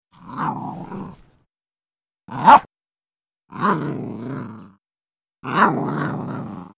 На этой странице собраны звуки собак, играющих с игрушками: лай, повизгивание, рычание и другие забавные моменты.
Звук щенка, грызущего игрушку